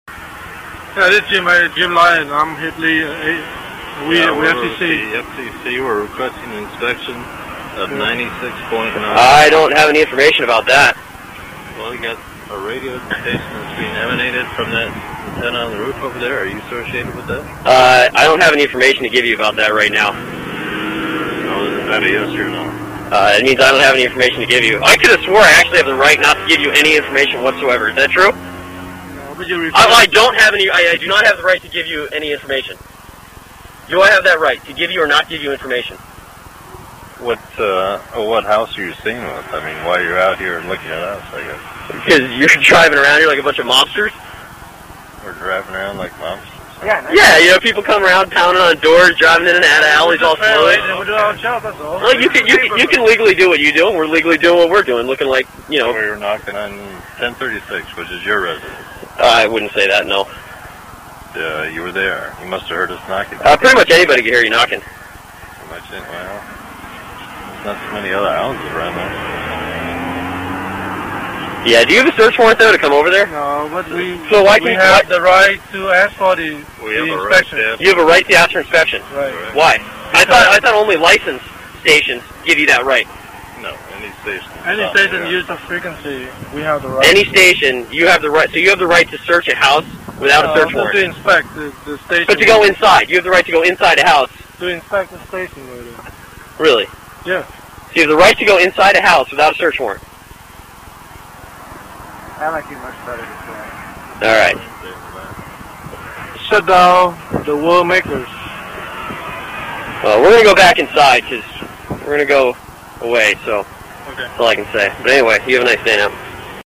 Recorded in the street, March 9, 2003: "Shut down, or we'll make you."